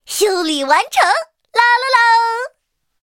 M4A3E2小飞象修理完成提醒语音.OGG